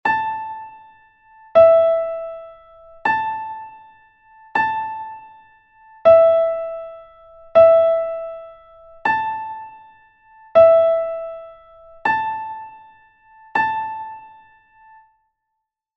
note recognition exercise 1